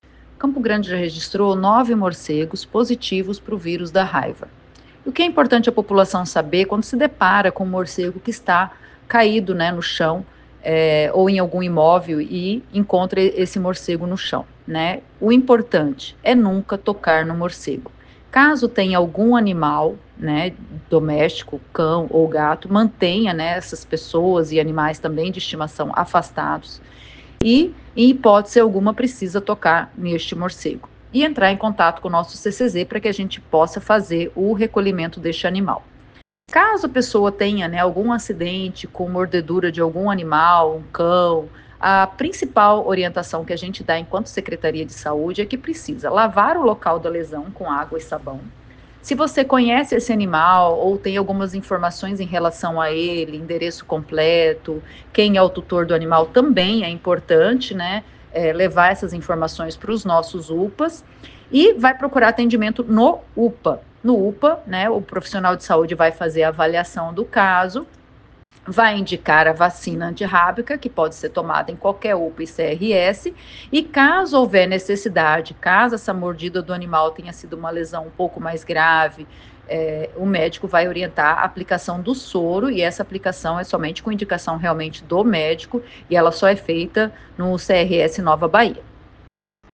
Em entrevista à Rádio FM 104,7 Educativa